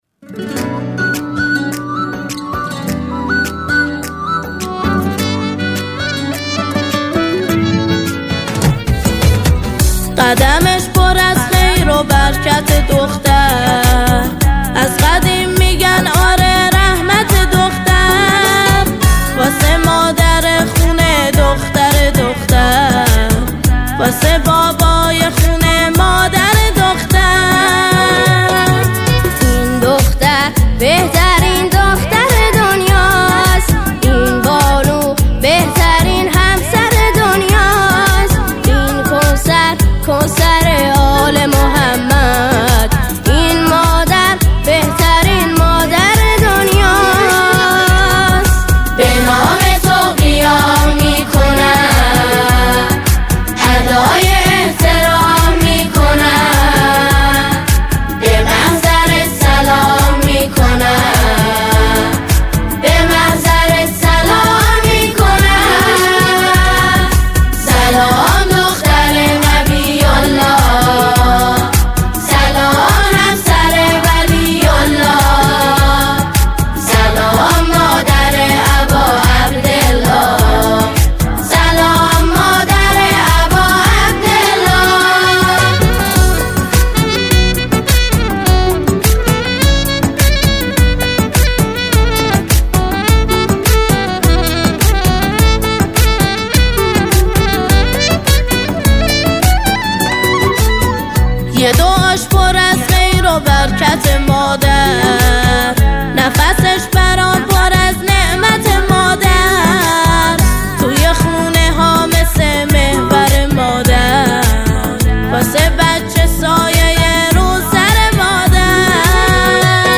با اجرای مشترک و شادمانه